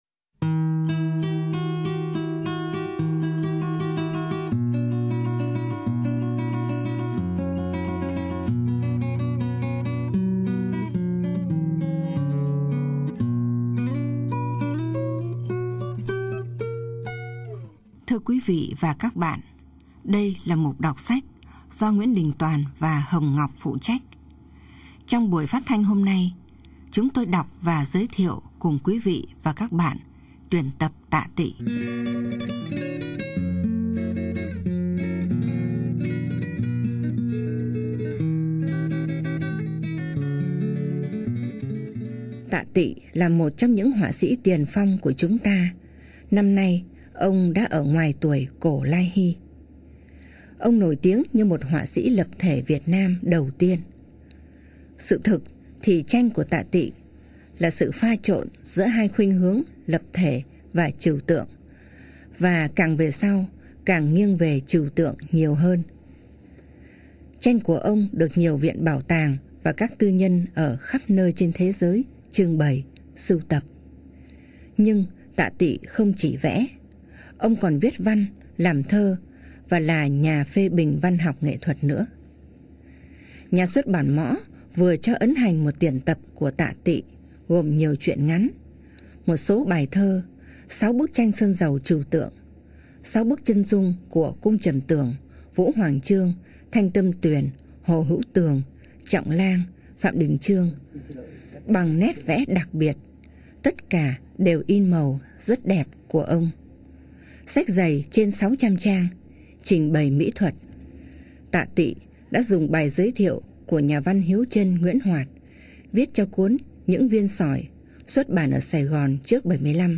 Ở cái giới hạn của thời lượng phát thanh, giọng của người đọc trở thành một yếu tố quan trọng trong việc chuyển tải nội dung. Giọng NĐT không còn là giọng một thanh niên ủ rũ héo sầu ẩm ướt sương buồn tới nỗi sém nhão nhẹt, và những lời ông viết không còn là những lời diễm ảo cho tới nỗi sém cải lương.